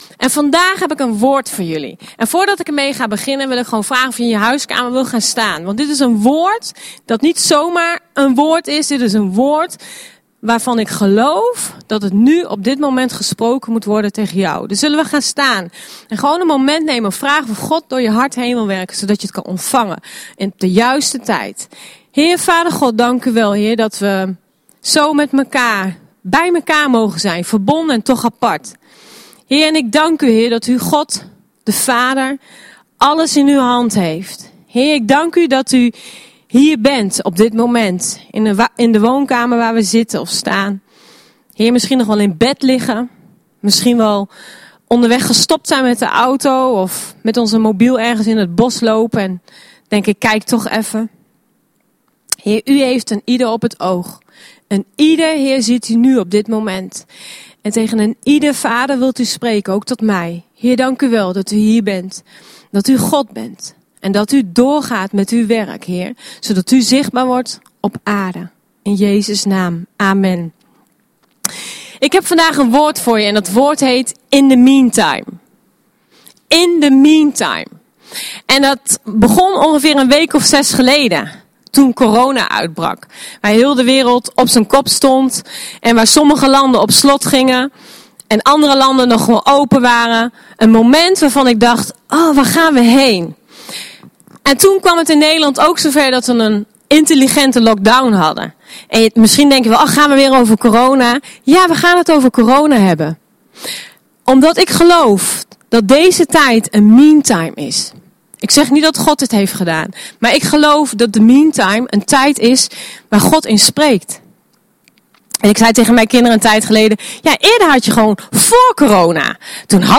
Luister hier de dienst terug!